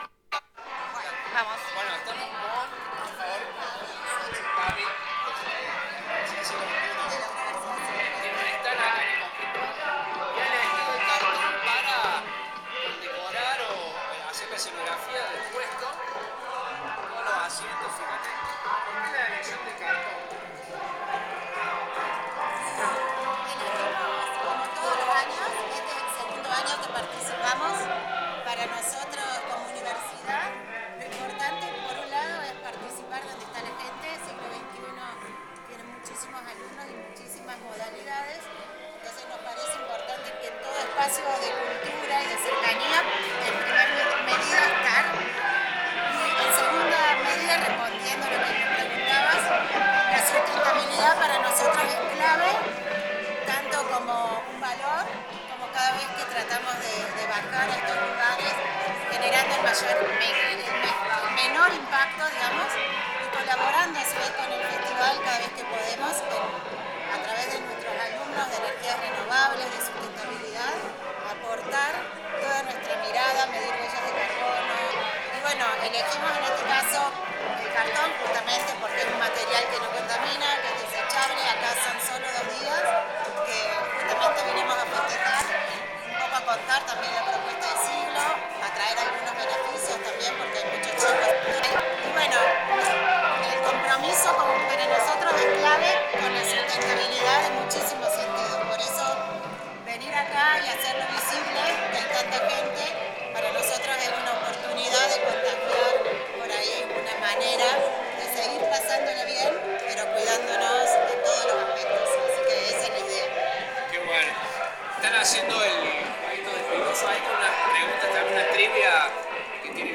Radio del Monte en el Cosquín Rock 2023
A continuación, les compartimos el audio de la entrevista.